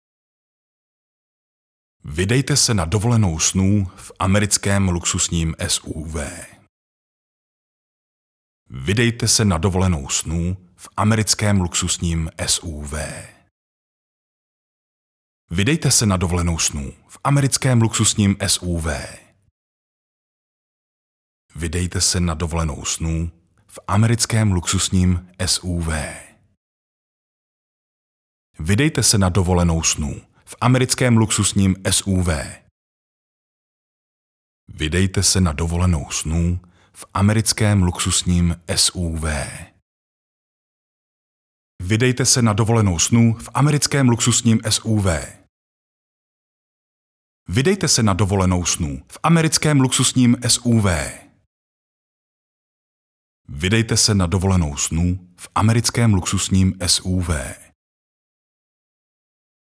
Součástí každého jobu je i základní postprodukce, tedy odstranění nádechů, filtrování nežádoucích frekvencí a ekvalizace a nastavení exportu minimálně 96kHz/24bit, okolo -6dB, jestli se nedohodneme jinak.
Mužský voiceover - hlas do krátkých reklamních spotů!